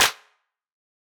Clap Groovin 3.wav